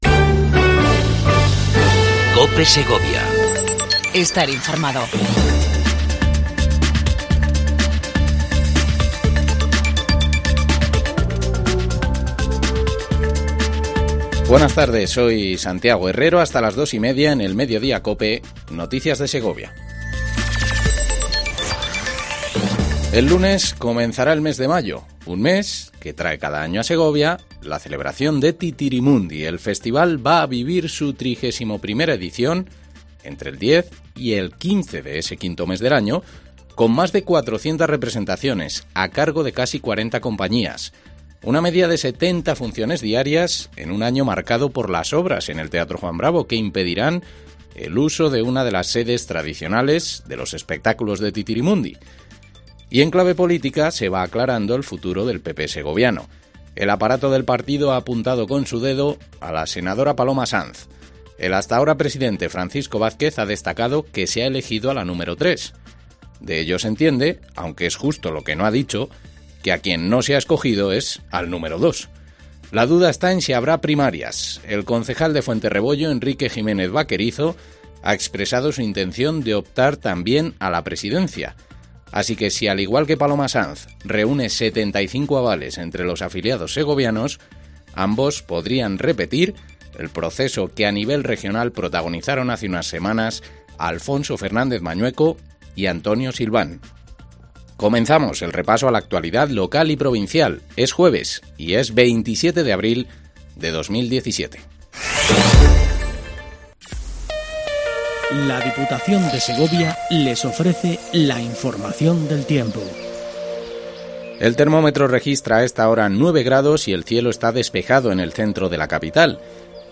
INFORMATIVO MEDIODIA COPE EN SEGOVIA 27 04 17